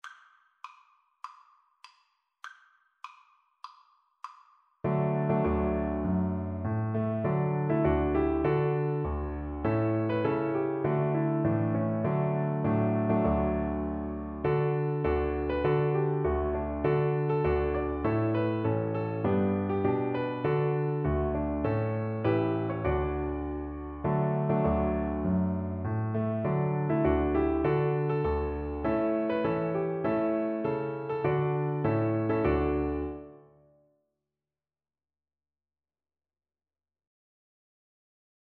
A4-D6
4/4 (View more 4/4 Music)